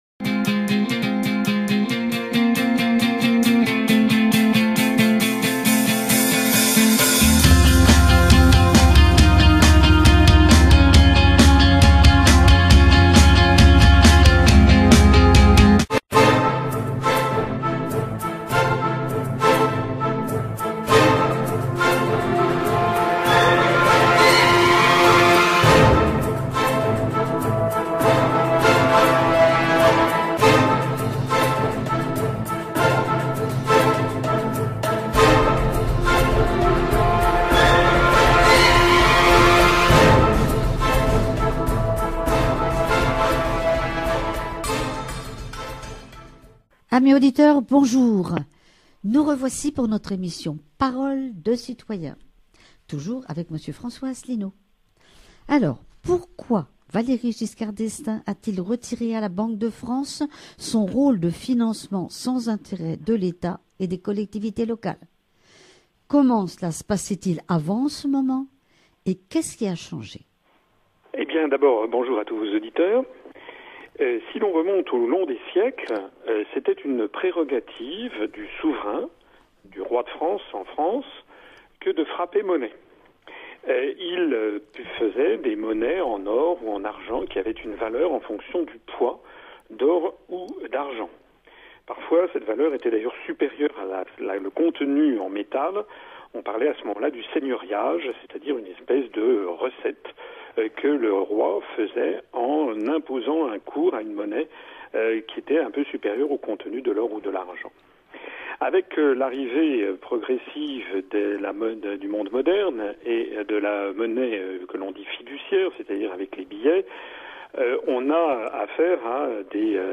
Il s’agissait d’un entretien téléphonique assez long et technique, au format audio uniquement et d’ailleurs pas forcément très bien enregistré, mais j’oscillais beaucoup entre Nice et Monaco en voiture à l’époque et, le temps étant toujours interminable sur l’autoroute, cela m’occupait bien.